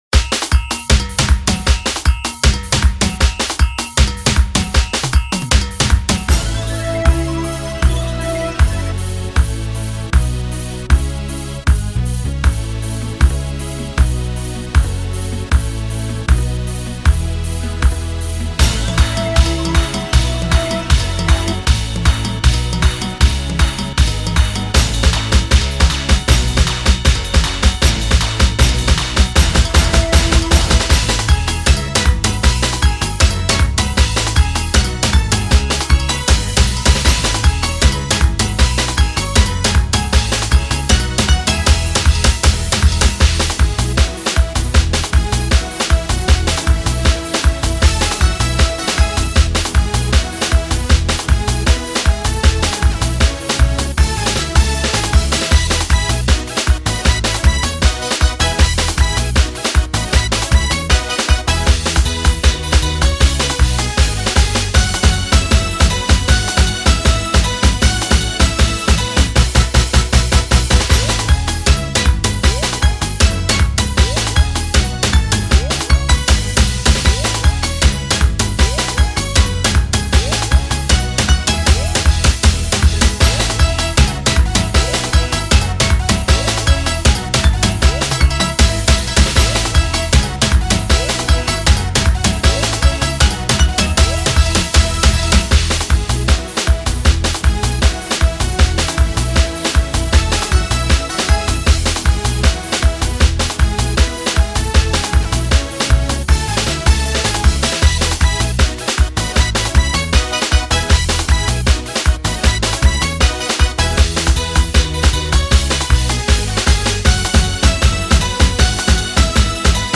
(Soca 2006) (Instrumental) Download